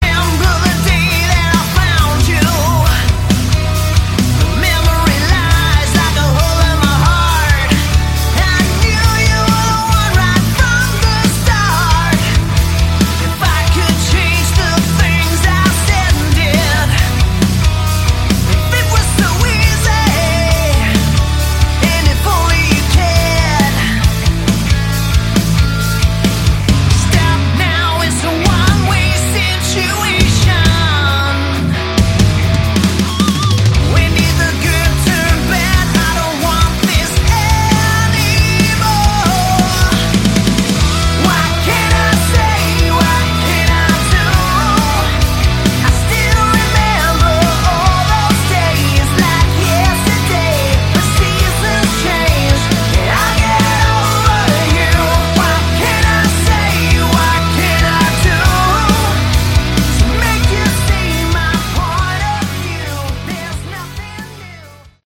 Category: AOR
vocals
keyboards, piano, percussion
guitar, acoustic guitar
bass, acoustic guitar